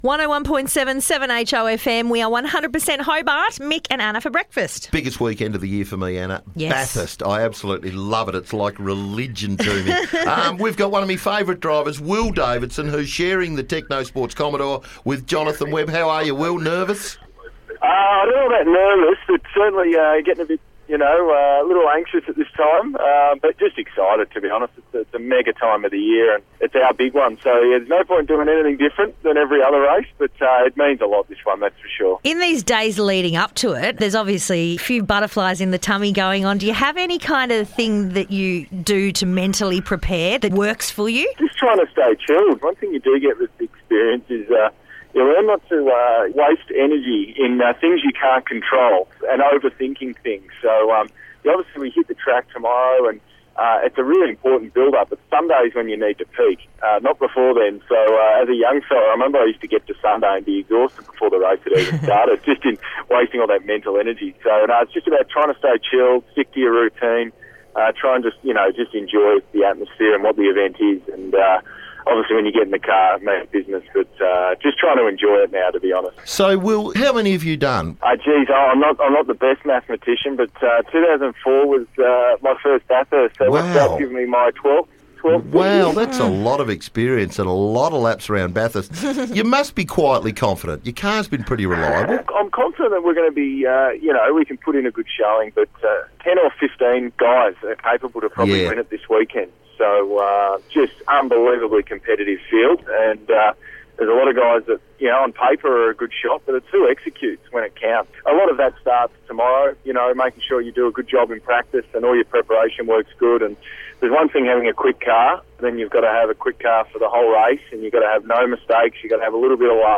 We caught up with driver Will Davison ahead of Bathurst this weekend